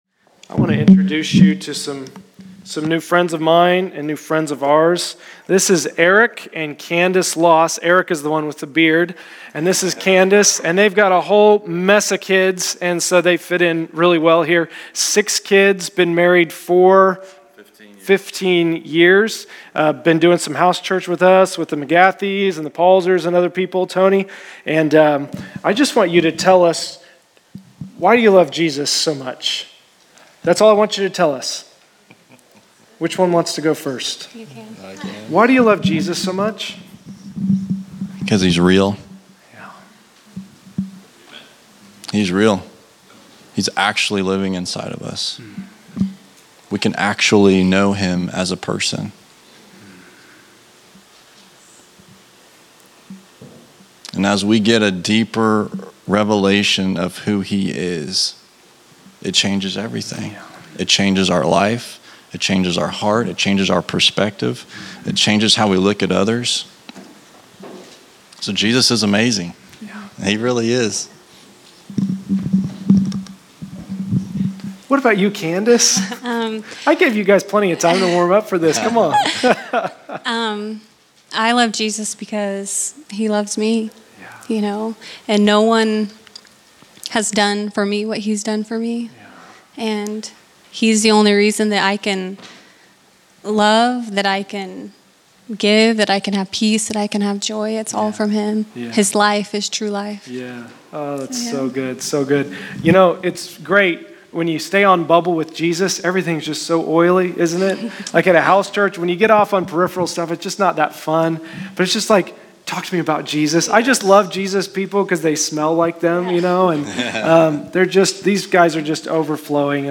November 07, 2020      Category: Testimonies      |      Location: El Dorado